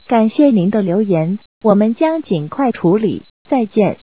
留言结束提示音.wav